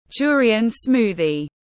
Sinh tố sầu riêng tiếng anh gọi là durian smoothie, phiên âm tiếng anh đọc là /ˈdʒʊə.ri.ən ˈsmuː.ði/
Durian smoothie /ˈdʒʊə.ri.ən ˈsmuː.ði/